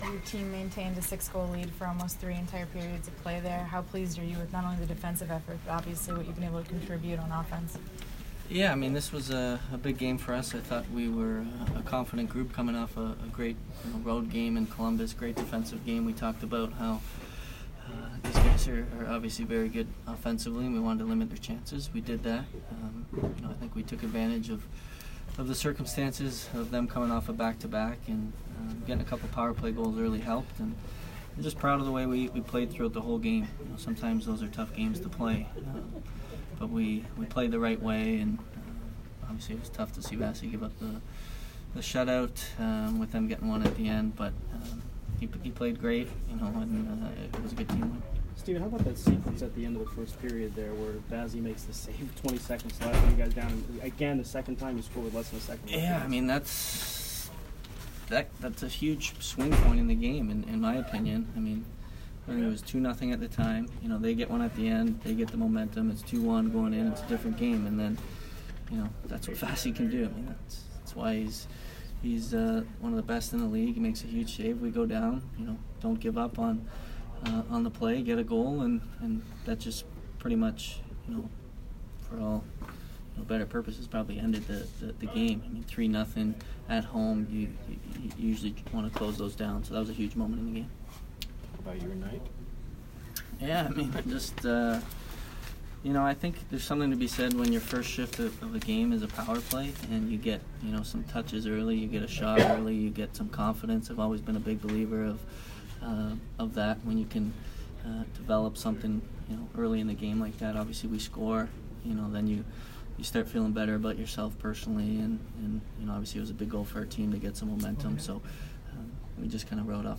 Steven Stamkos Post-Game 10/21